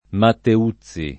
Matteuzzi [ matte 2ZZ i ] cogn.